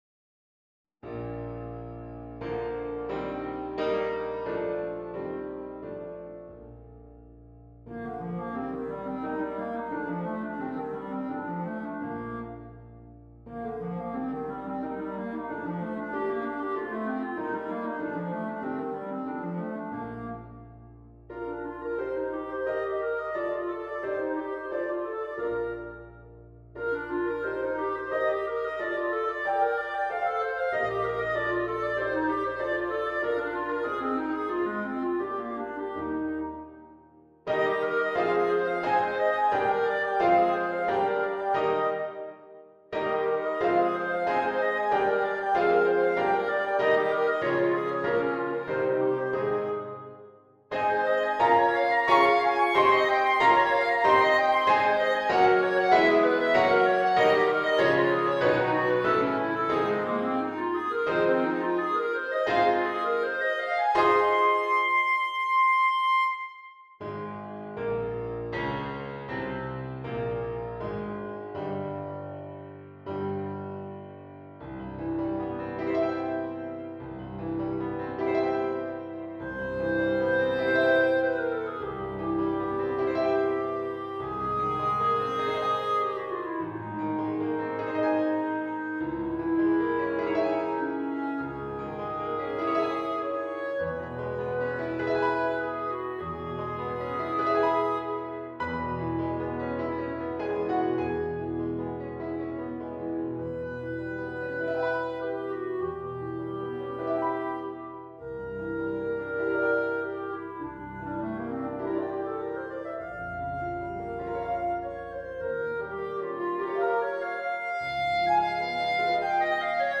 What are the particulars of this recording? This file contains a complete performance and accompaniment.